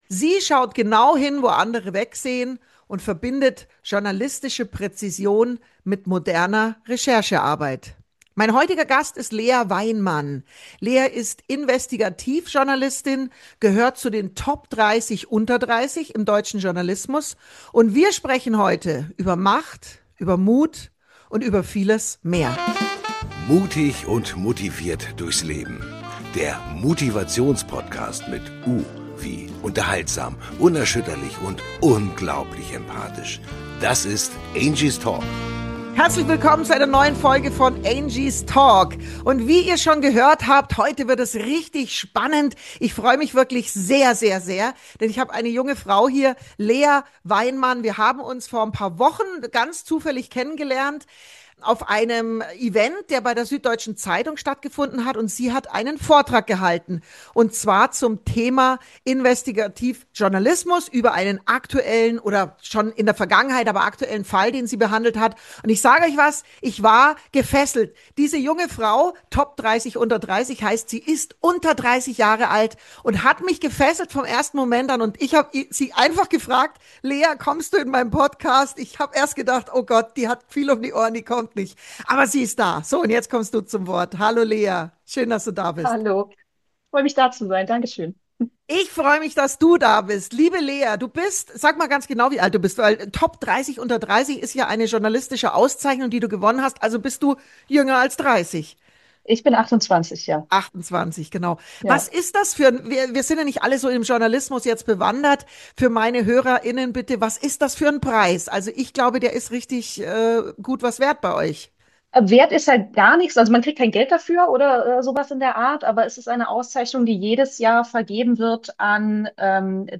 Ein Gespräch über